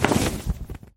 Wings Enderdragon 2